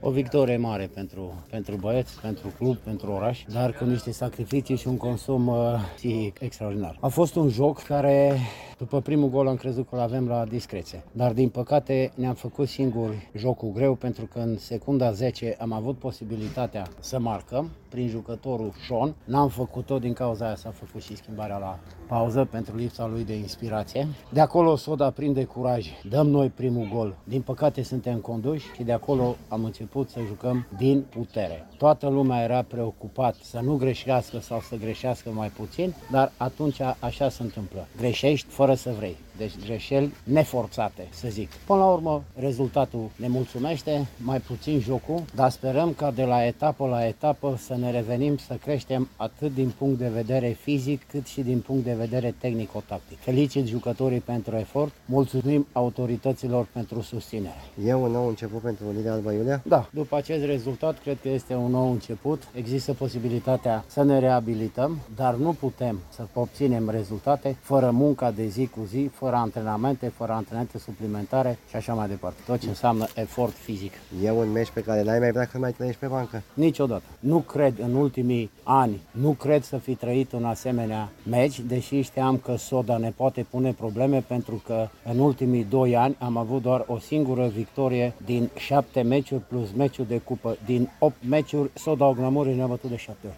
AUDIO | Prima victorie a Unirii Alba Iulia: Succes cu CS Ocna Mureș